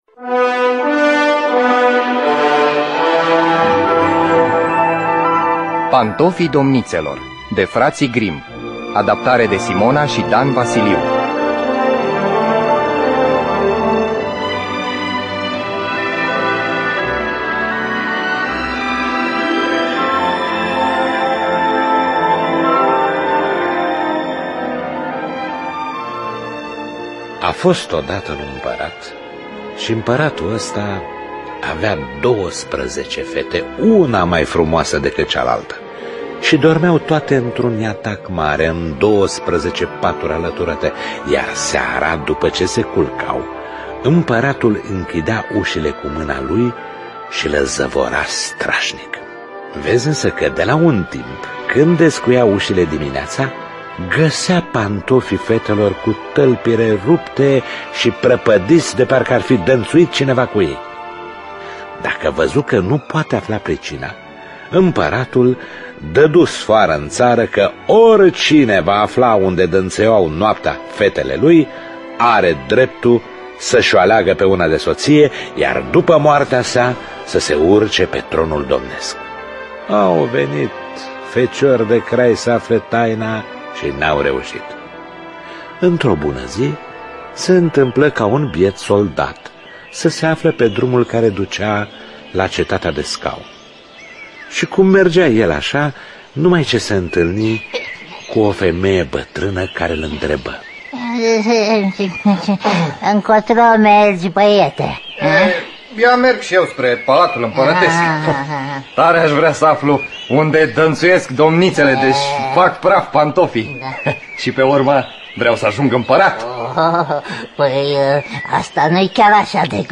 Adaptarea radiofonică de Simona și Dan Vasiliu.